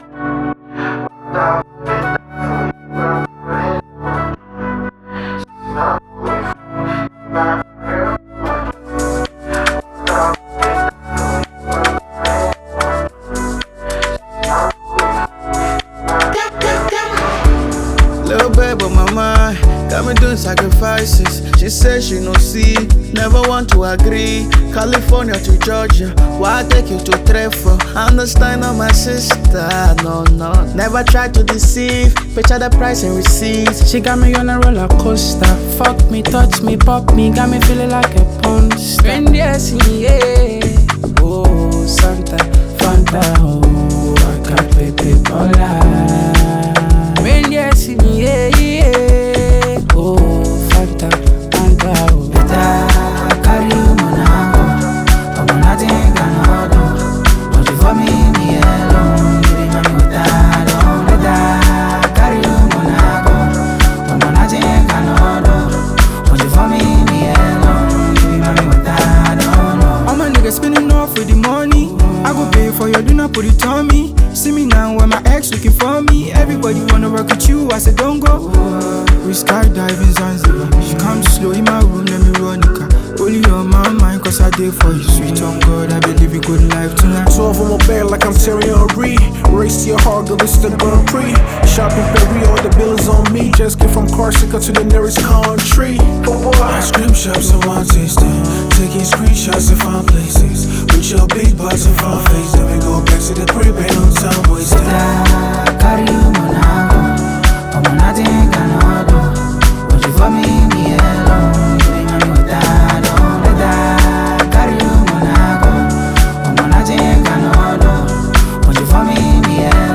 South African based Ghanaian Gospel musician